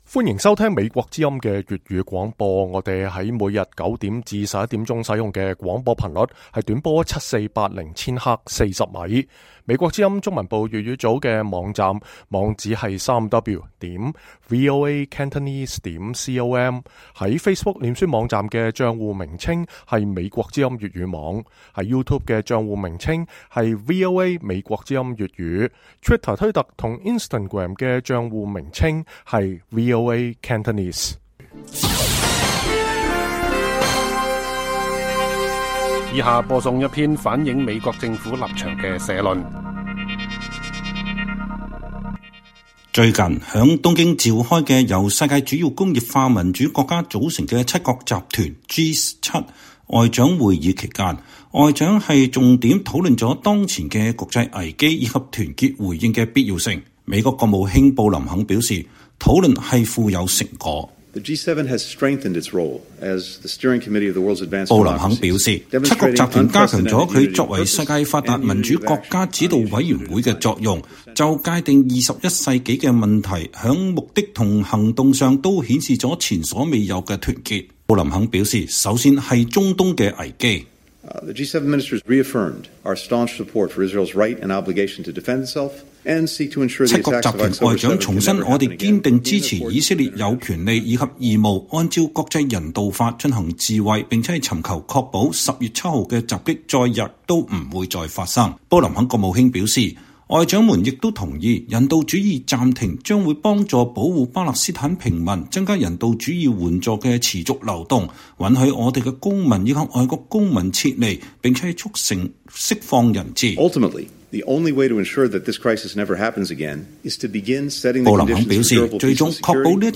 美國政府立場社論